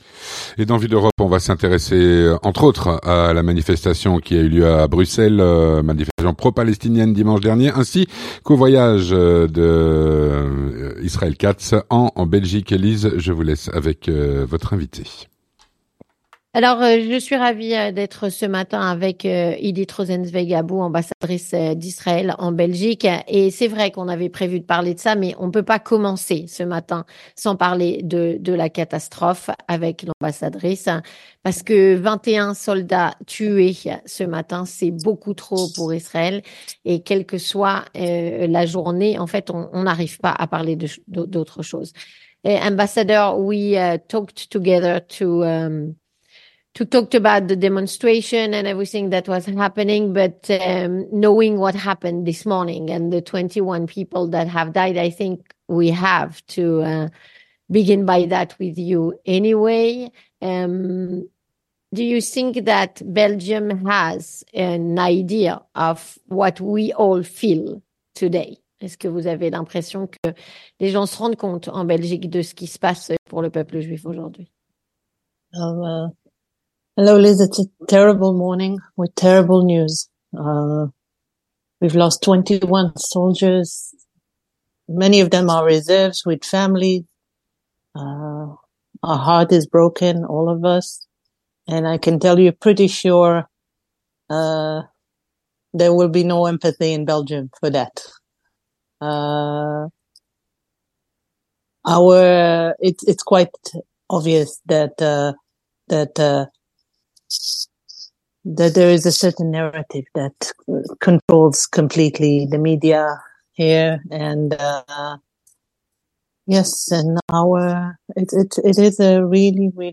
Avec S.E. Idit Rosenzweig-Abu, Ambassadrice d'Israël en Belgique